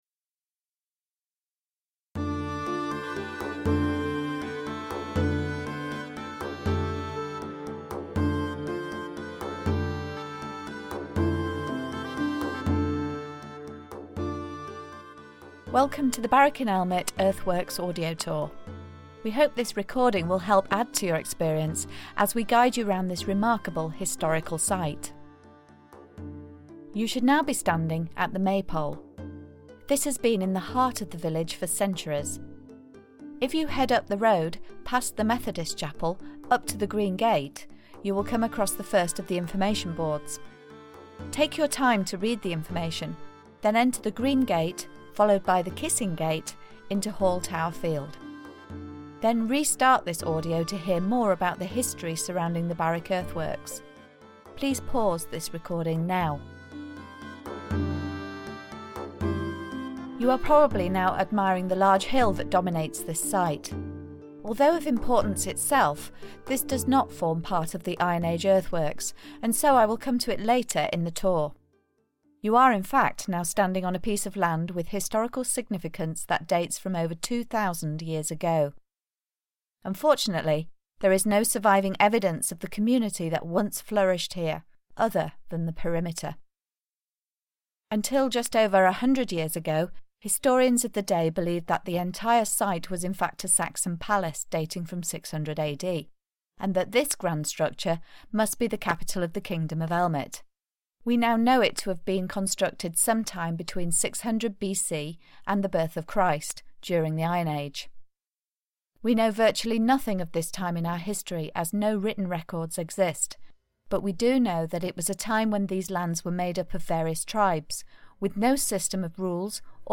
Podcasttour